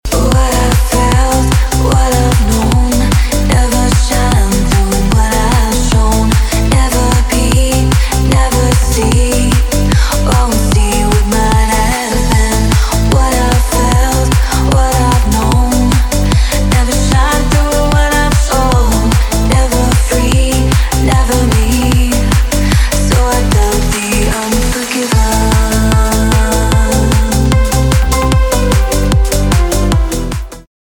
• Категория: Клубные рингтоны